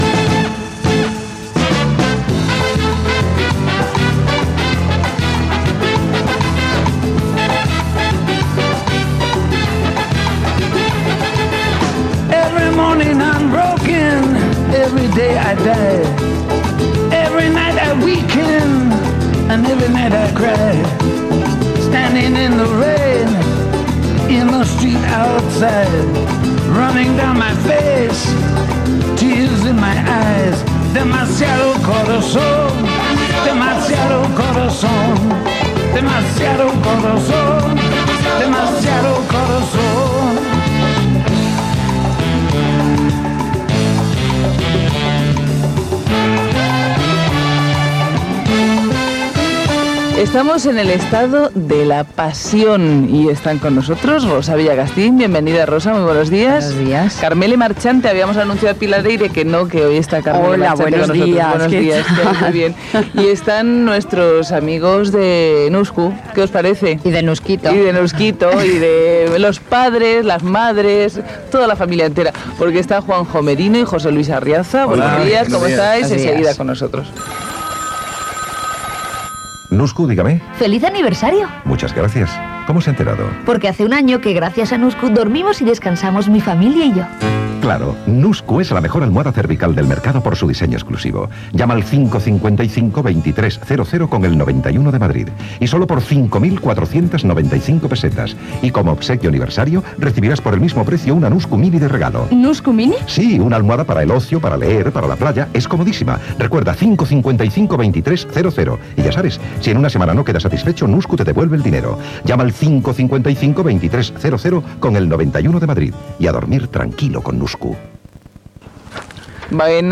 55db3098be140ebbca6571ae53b1d6e90de09a07.mp3 Títol Antena 3 Radio Emissora Antena 3 de Barcelona Cadena Antena 3 Radio Titularitat Privada estatal Nom programa Días de radio Descripció Societat amb Rosa Villacastín i Karmele Marchante: baró Thyssen. Gènere radiofònic Entreteniment
Presentador/a García Campoy, Concha